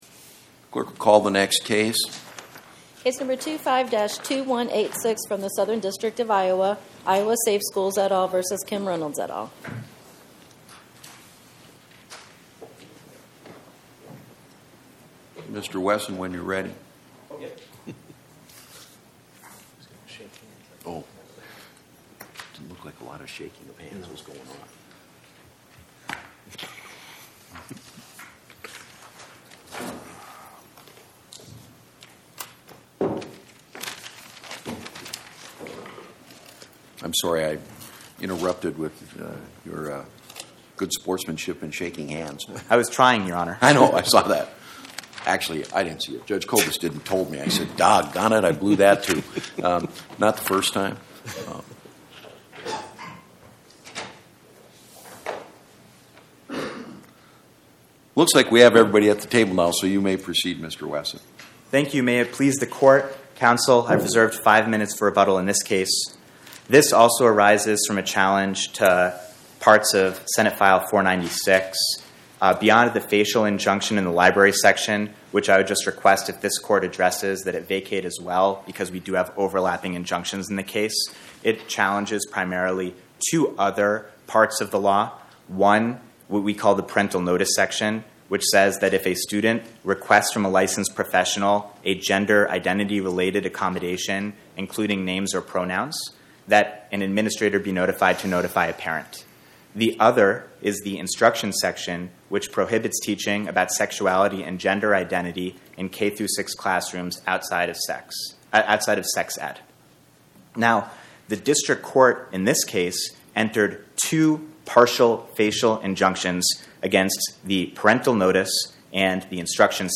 My Sentiment & Notes 25-2186: Iowa Safe Schools vs Kim Reynolds Podcast: Oral Arguments from the Eighth Circuit U.S. Court of Appeals Published On: Tue Jan 13 2026 Description: Oral argument argued before the Eighth Circuit U.S. Court of Appeals on or about 01/13/2026